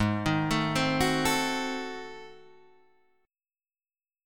G#7b9 chord